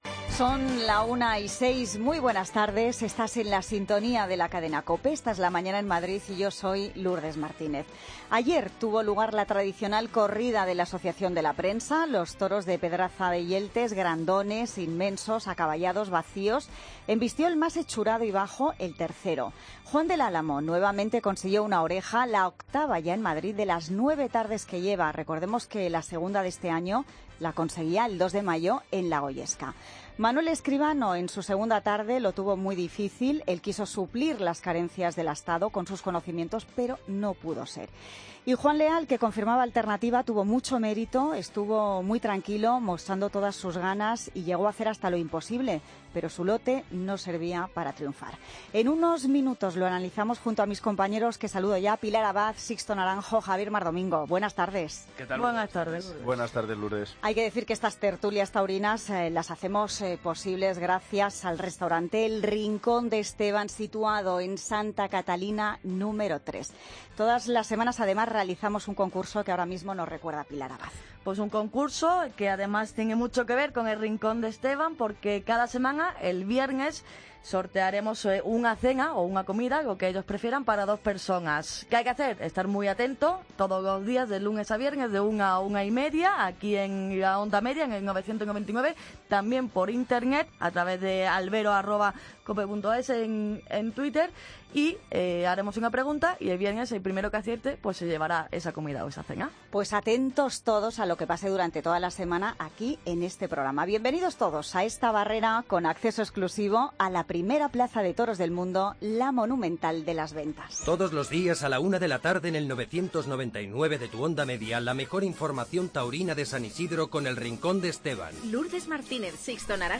Tertulia Taurina Feria San Isidro COPE Madrid, miércoles 18 de mayo de 2016